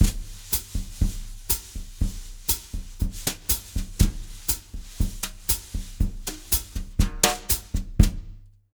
120BOSSA04-L.wav